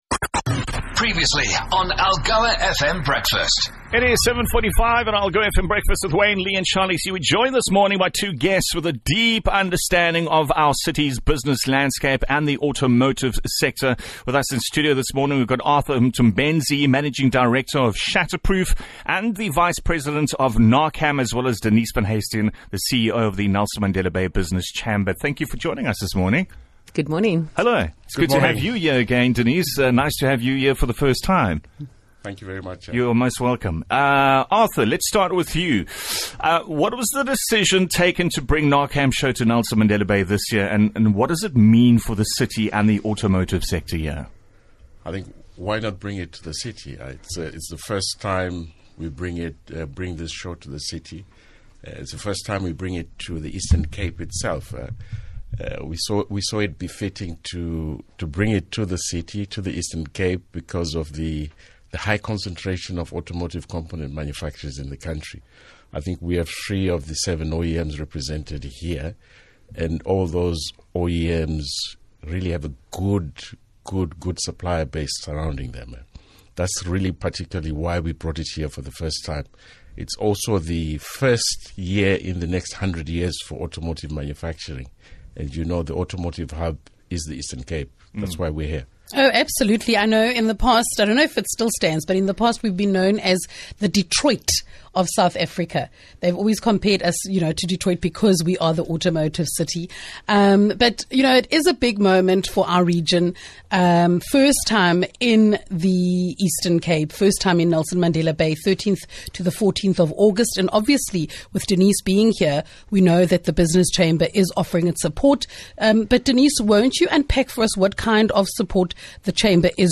Expect thought provoking interviews, heartfelt stories that impact Algoa Country positively and laughter that will set your day off right.